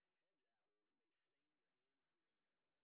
sp08_street_snr10.wav